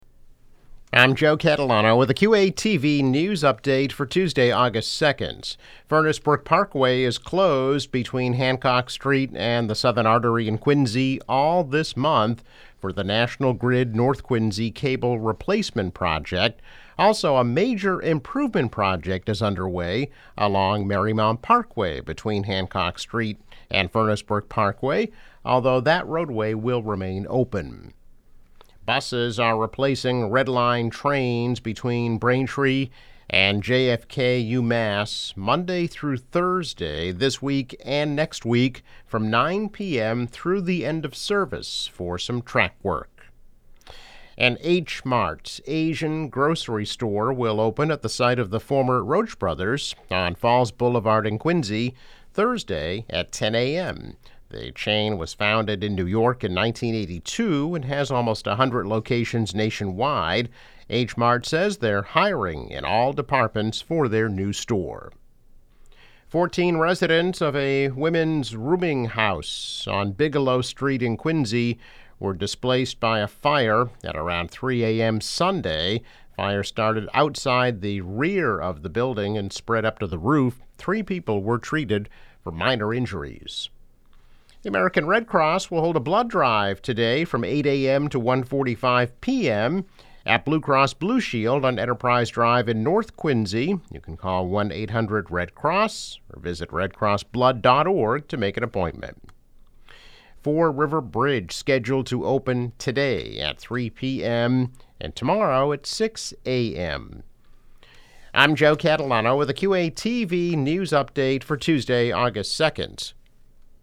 News Update - August 2, 2022